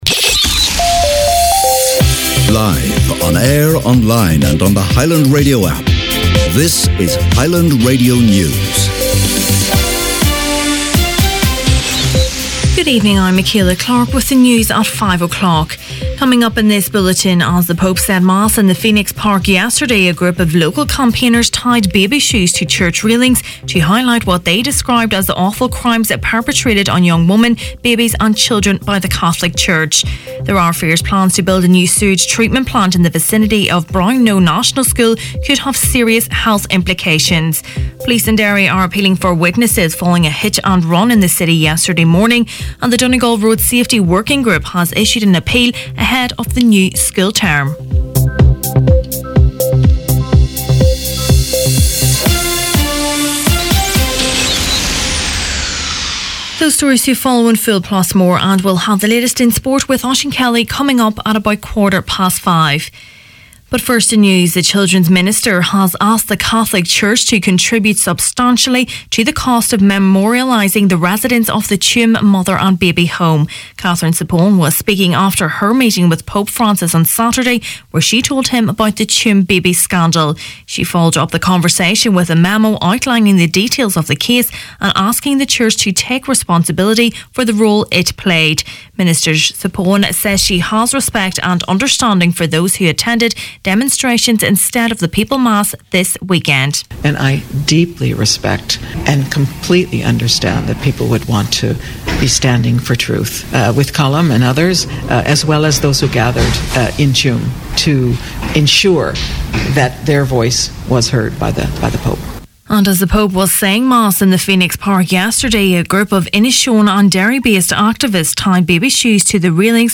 Main Evening News, Sport and Obituaries Monday August 27th